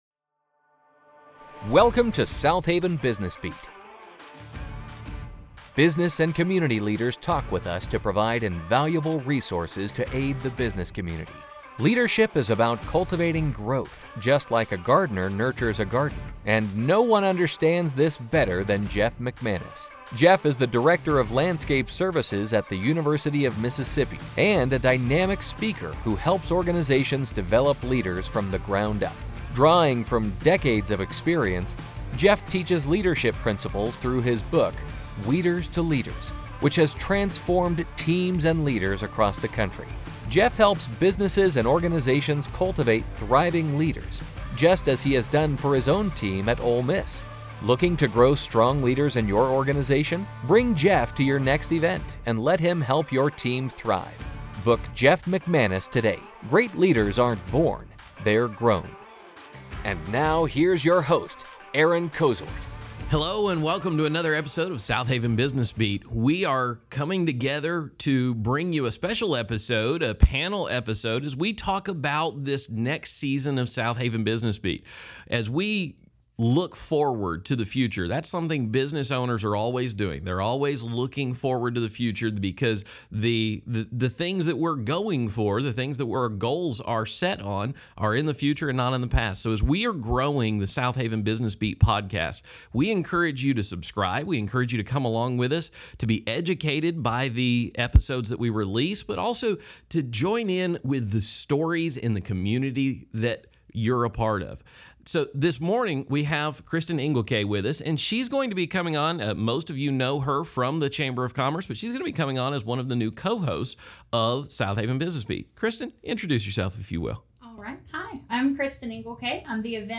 Welcome to a special panel episode of the Southaven Business Beat! In this episode, we discuss the podcast’s future, focusing on how business owners always look ahead to achieve ...